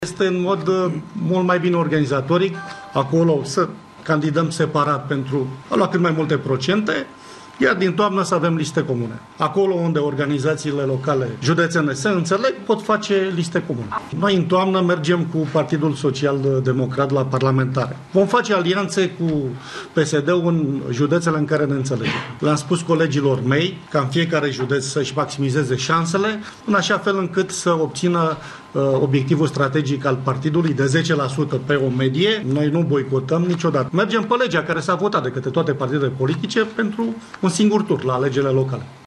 Liderii UNPR s-au hotărât: nu vor boicota alegerile locale. Decizia a fost luată la Brașov, unde s-a reunit conducerea formațiunii, și a fost prezentată de liderul partidului. Gabriel Oprea a mai spus că Uniunea pentru Progres va lansa pentru alegerile din 5 iunie candidați proprii și nu va face liste comune cu PSD: